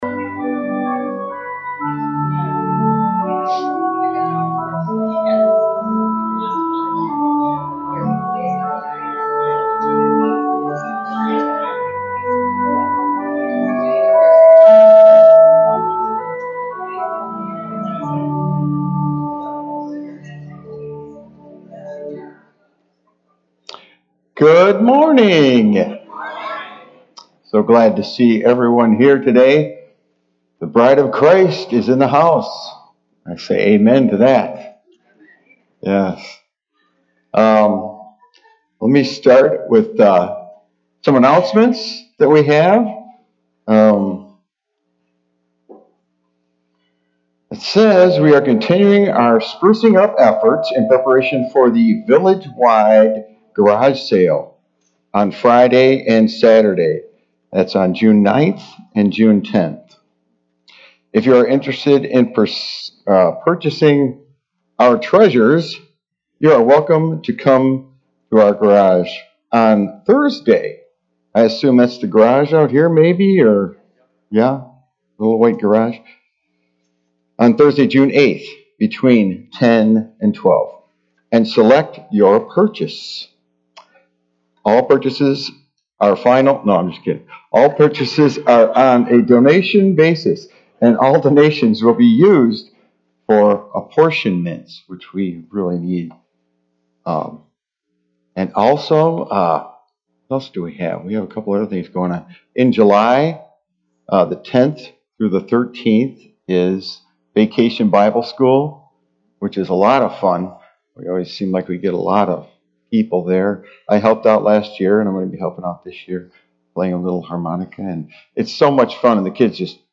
RUMC-service-June-4-2023.mp3